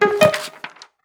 mailopen.wav